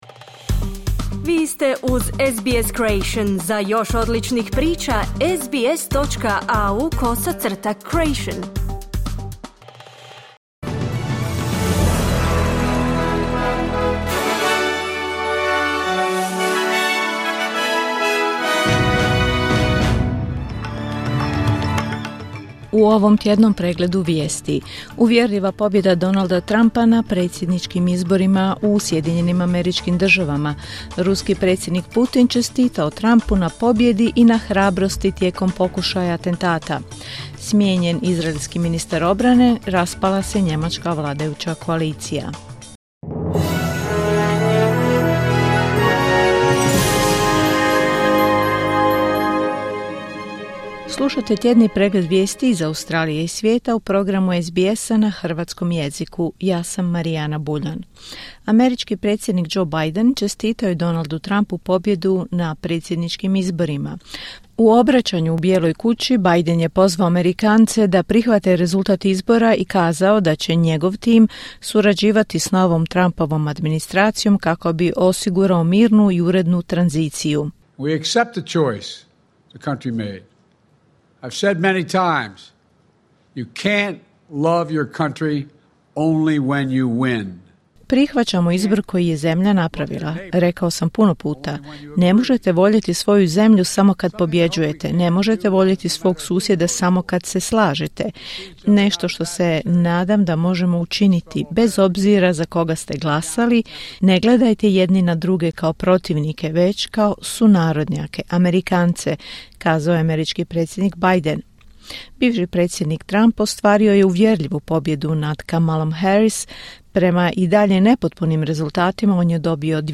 Tjedni pregled vijesti, 8.11.2024.